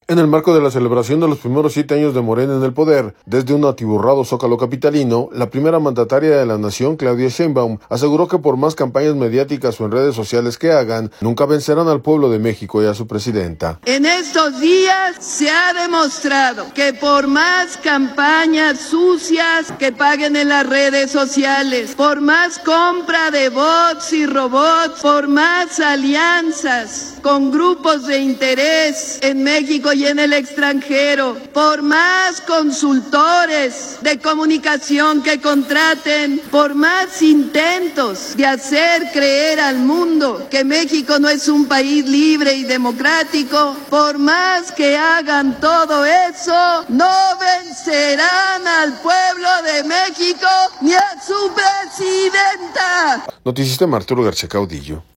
audio En el marco de la celebración de los primeros siete años de Morena en el poder, desde un atiborrado zócalo capitalino, la Primera Mandataria de la Nación, Claudia Sheinbaum, aseguró que por más campañas mediáticas o en redes sociales que hagan, nunca vencerán al pueblo de México y a su presidenta.